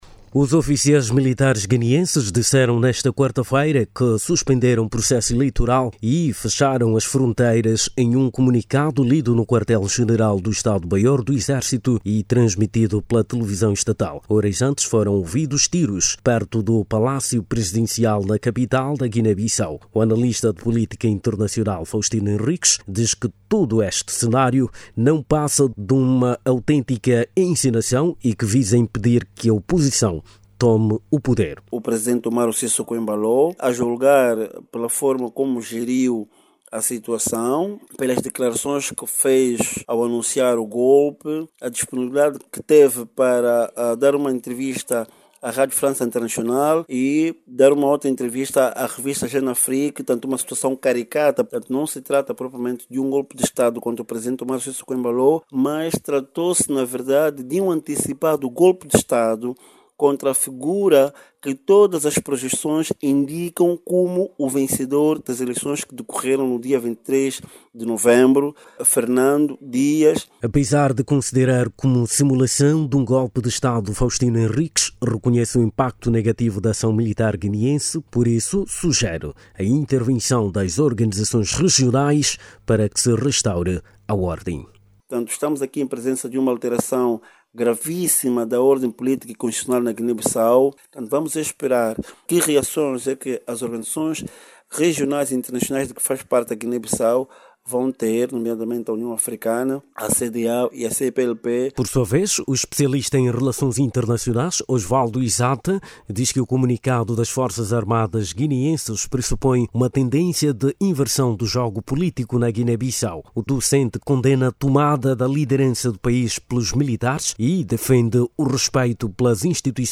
Analista de política internacional fala de simulação de golpe de Estado com a conivência de Umaro Sissocó Embaló